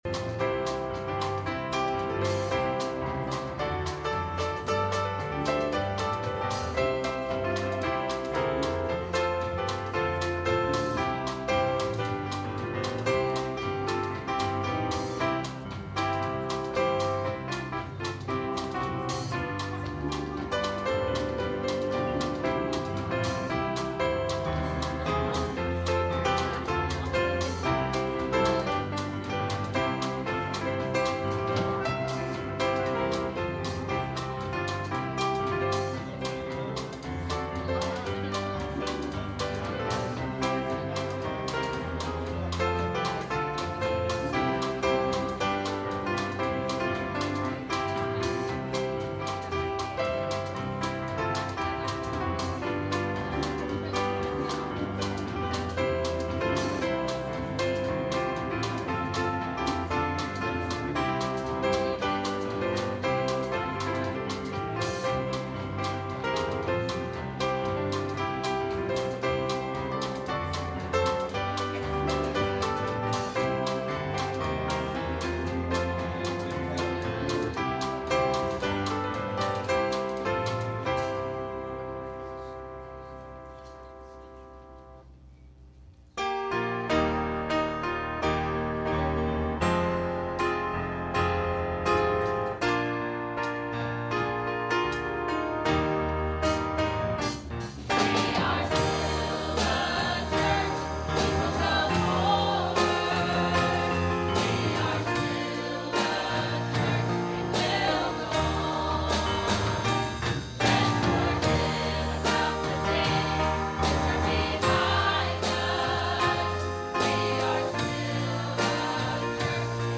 Message Service Type: Sunday Morning « Who’s Welcome At Your Table